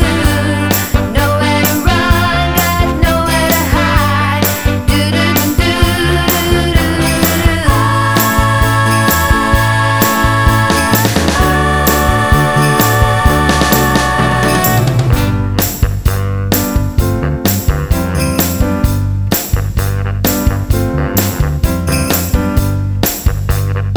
No Backing Vocals Soul / Motown 2:53 Buy £1.50